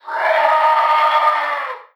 effect__game_over_scream.wav